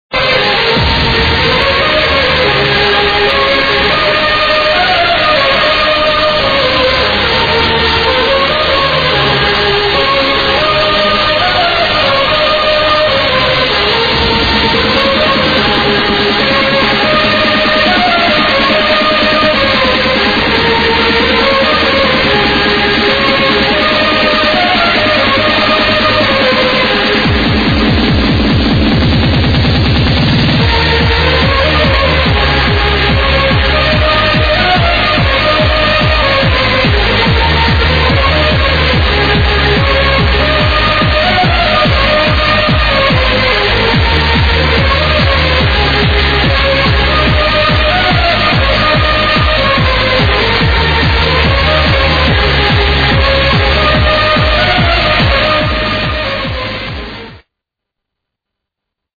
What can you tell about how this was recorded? It still sounds good for the ID.